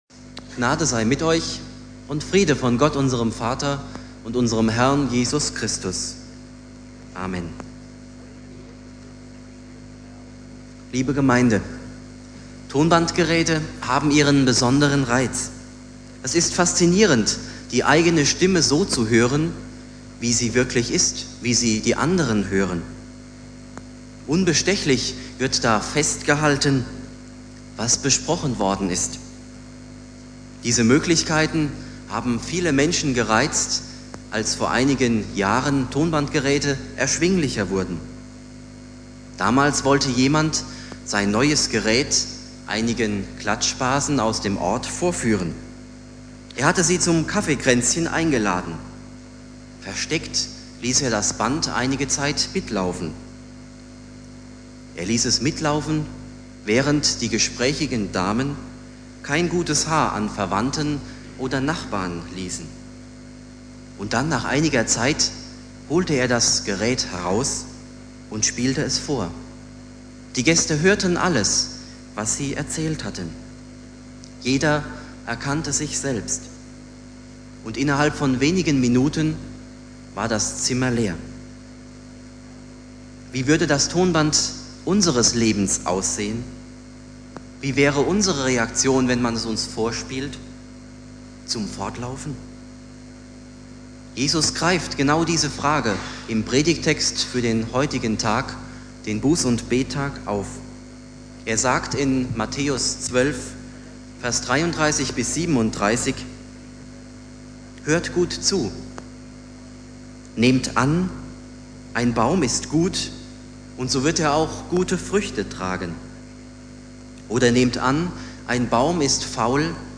Buß- und Bettag
Relativ schlechte Aufnahmequalität - die Stimmen im Hintergrund stammen von einem Mittelwellen-Radiosender, der durch ein nicht gut abgeschirmtes Mikrofonkabel hörbar wurde.